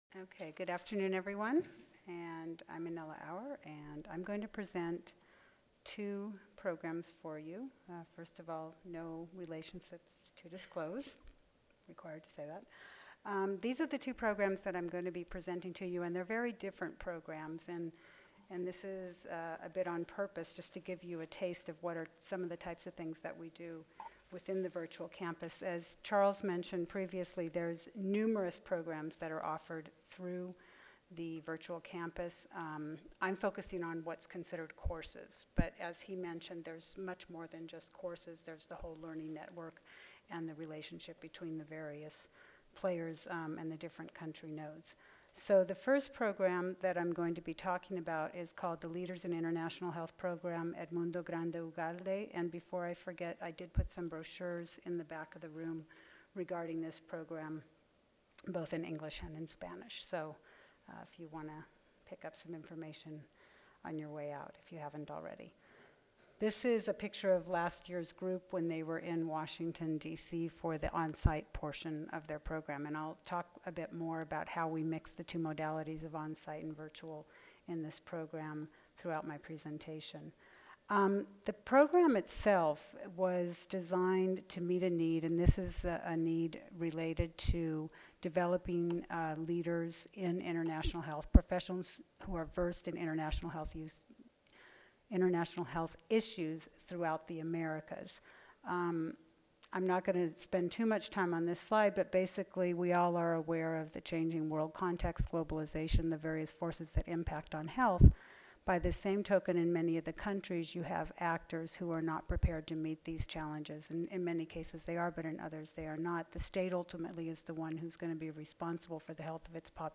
4159.0 Examples of effective distance/technology based training for the public health workforce in urban and rural settings Tuesday, November 9, 2010: 12:30 PM - 2:00 PM Oral This session will present examples of different settings where distance/technology based training has proved effective in delivery continuing education to public health practitioners in rural and urban settings. Experts from the Pan American Health Association (PAHO)World Health Organization (WHO) will share examples of some of their successful distance learning programs. The session moderator will lead a discussion on strategies/methods to ensure that topics presented via distance learning can be effectively presented via distance technology.